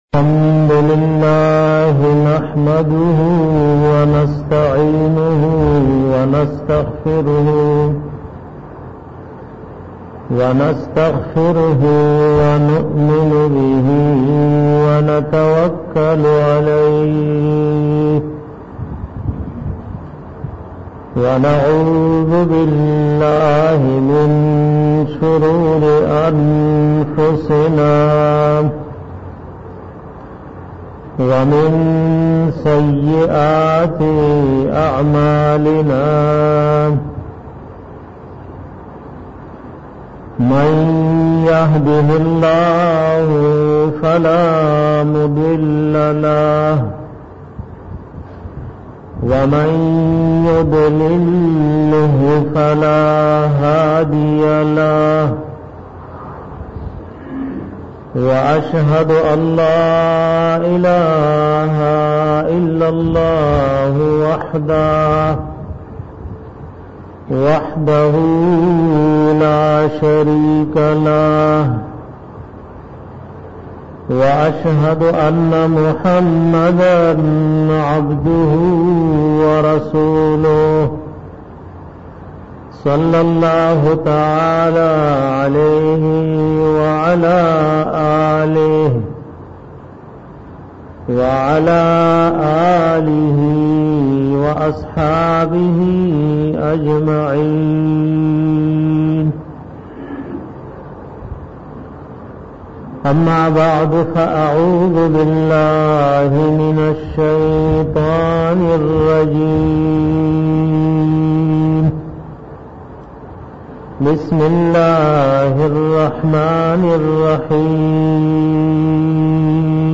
bayan sa ilam da quran ao maqsad